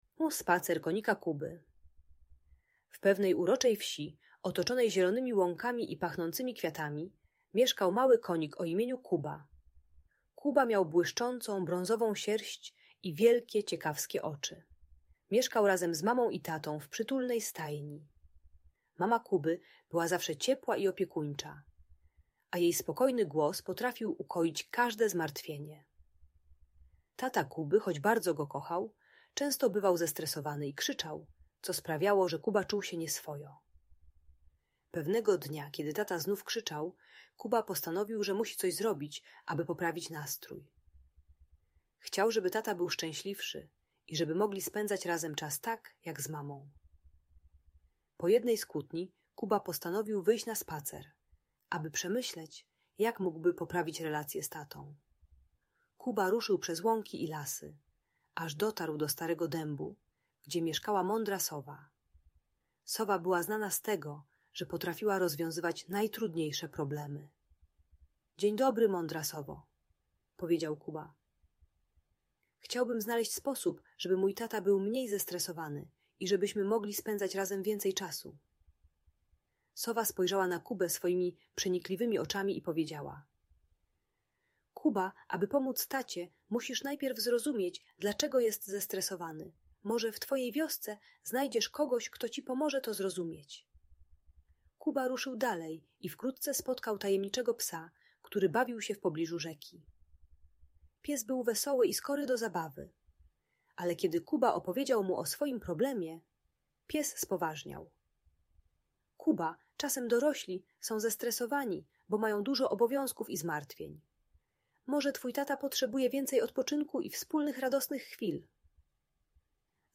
Audiobajka o trudnych emocjach w rodzinie.